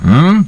huhn.mp3